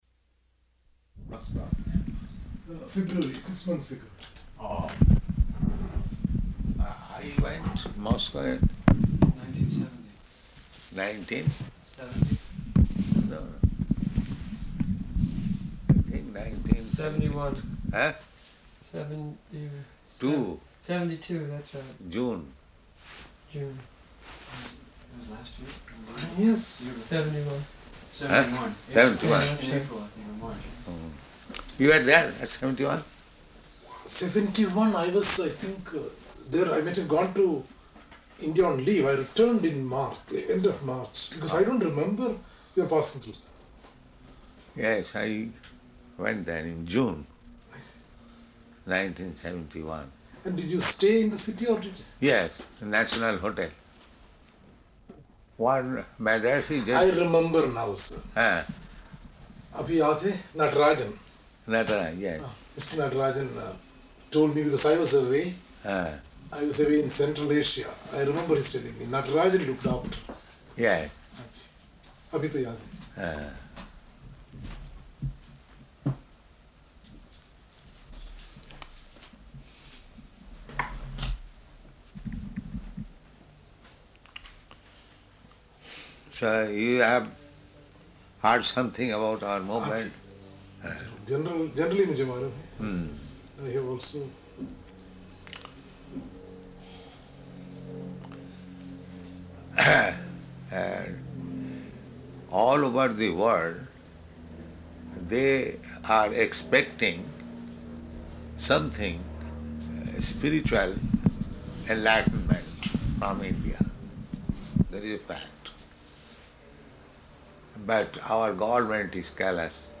Room Conversation with Indian Ambassador
Room Conversation with Indian Ambassador --:-- --:-- Type: Conversation Dated: September 5th 1973 Location: Stockholm Audio file: 730905R1.STO.mp3 Prabhupāda: When you left Moscow?